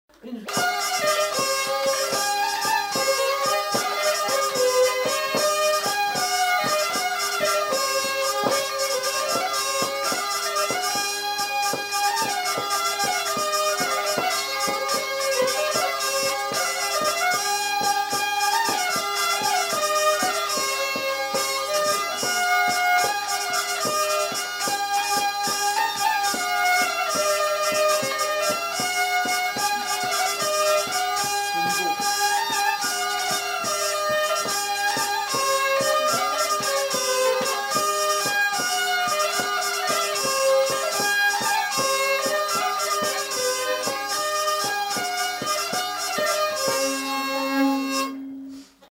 Aire culturelle : Limousin
Genre : morceau instrumental
Instrument de musique : violon ; vielle à roue
Danse : valse
Notes consultables : La vielle à roue est jouée par un des enquêteurs.